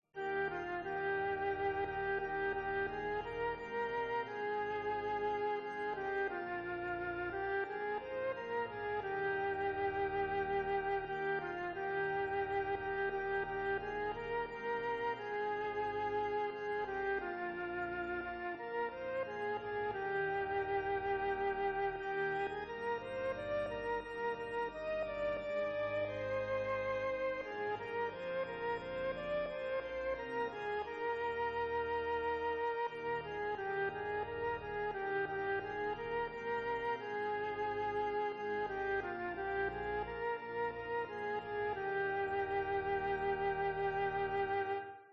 Melody and descant for C instrument
Instrumental C instrument (or voice, filling in text)
Descant Descant